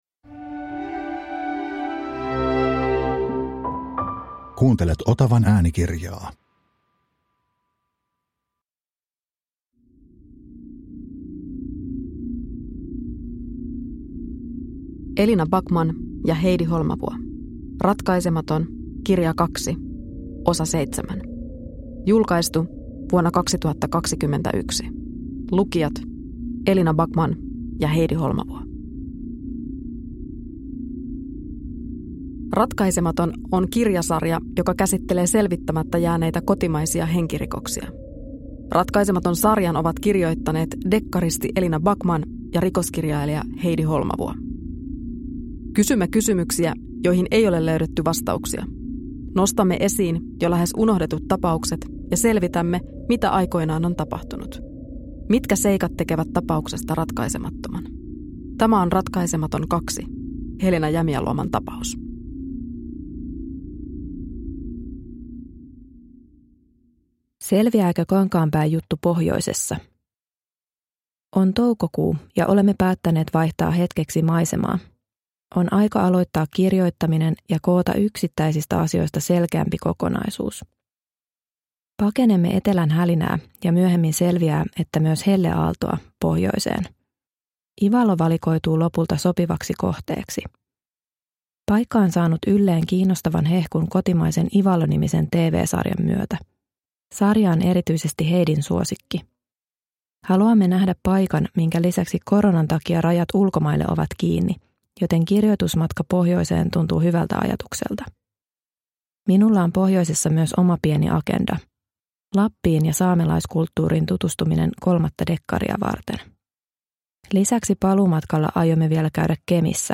Ratkaisematon 7 (ljudbok) av Heidi Holmavuo